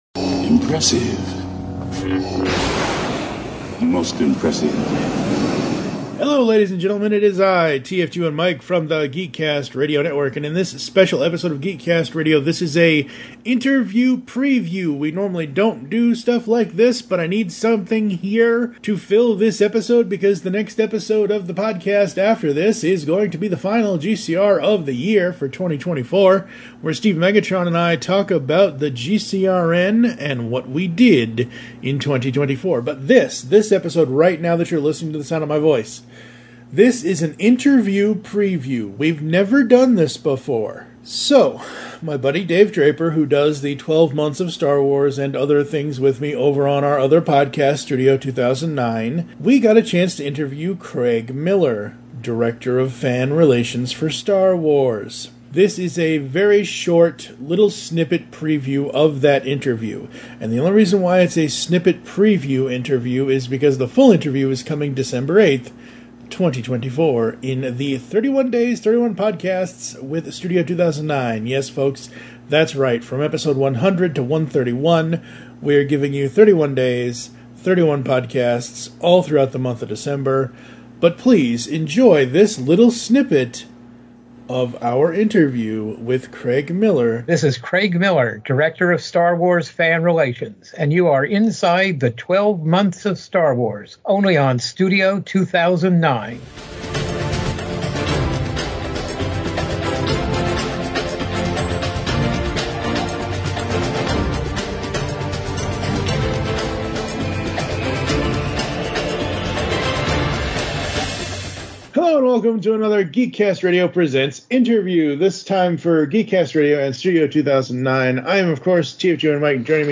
GeekCast Radio - 346 - Interview Previews